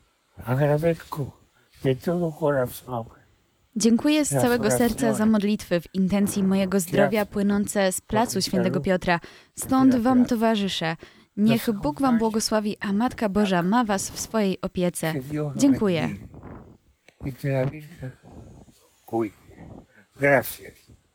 W środowy wieczór, 6 marca, o godzinie 21:00 na Placu św. Piotra wyemitowano pierwsze nagranie audio Papieża Franciszka ze szpitala. W swoim przesłaniu Ojciec Święty podziękował za modlitwy w intencji jego zdrowia i pobłogosławił wiernych:
papiez-z-tlumaczeniem.mp3